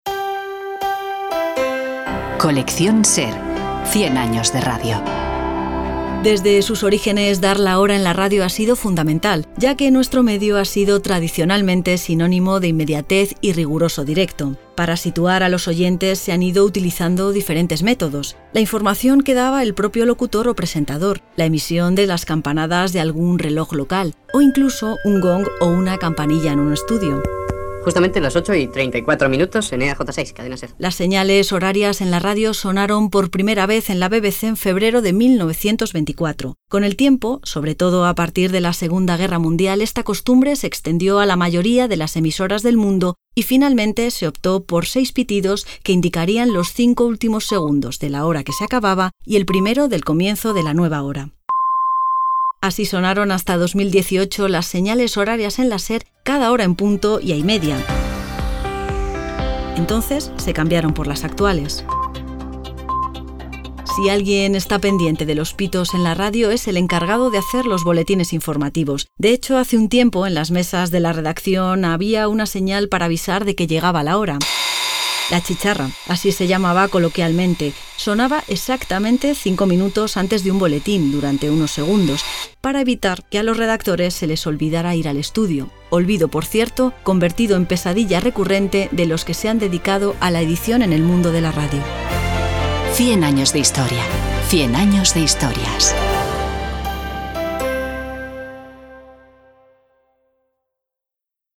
Señales horarias
Desde sus orígenes dar la hora en la radio ha sido fundamental, ya que nuestro medio ha sido tradicionalmente sinónimo de inmediatez y riguroso directo. Hasta llegar a las actuales señales horarias, para situar a los oyentes, a lo largo de la historia se han utilizado diferentes métodos: la información que daba el propio locutor, la emisión de las campanadas de algún reloj local o incluso un gong o una campanilla en el estudio.